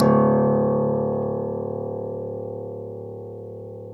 Index of /90_sSampleCDs/Club-50 - Foundations Roland/PNO_xTack Piano/PNO_xTack Pno 1D